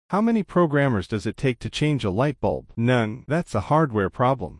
text_to_speech.mp3